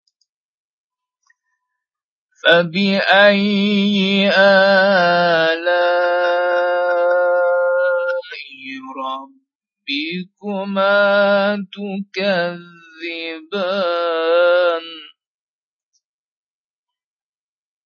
قرار-نهاوند.mp3